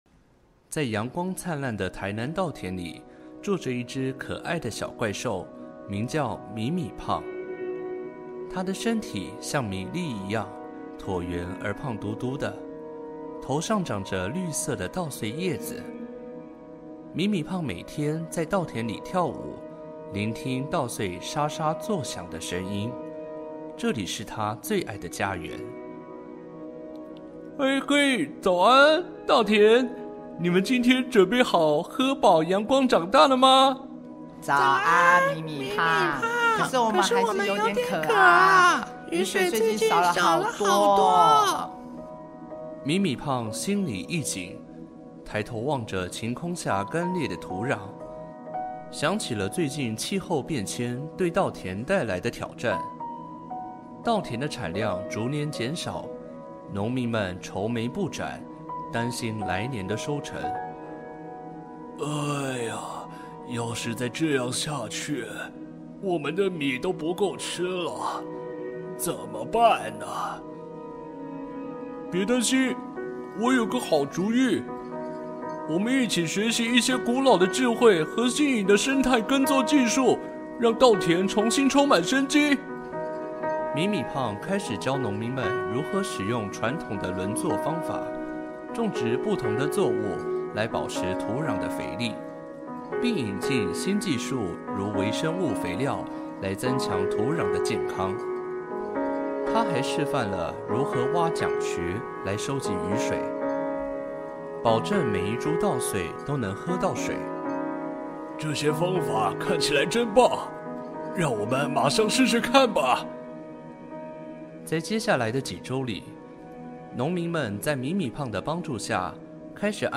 故事有聲書